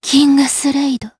DarkFrey-Vox_Kingsraid_jp.wav